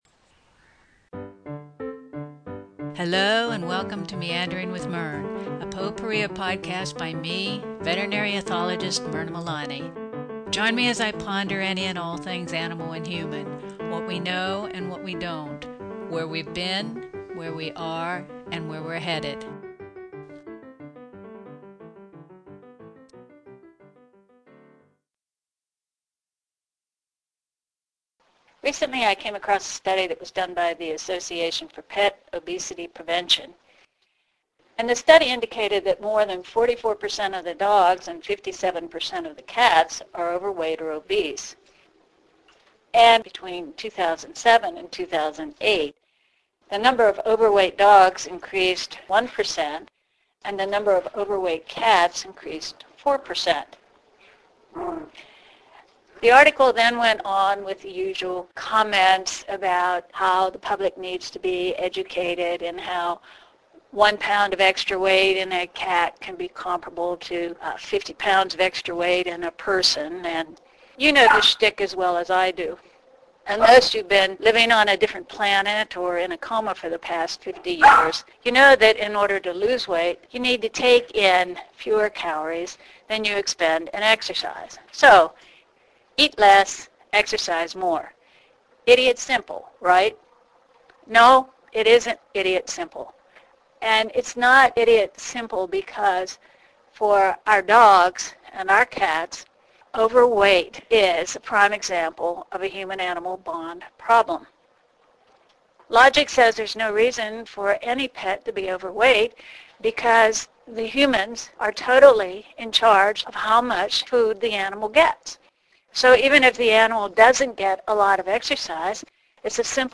Technically, in this podcast I continue to master the new recorder settings that will provide the best quality while enabling me to record while staying ahead of Ollie and BeeBee who still think the recorder means “Play time!!”